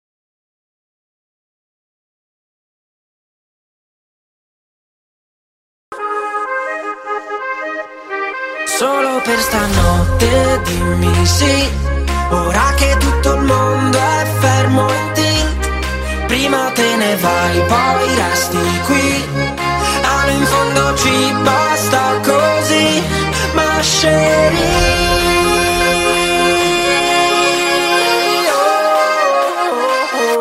Bjr chers élèves, afin de pouvoir vous entraîner, voici la bande son du refrain de la chorégraphie.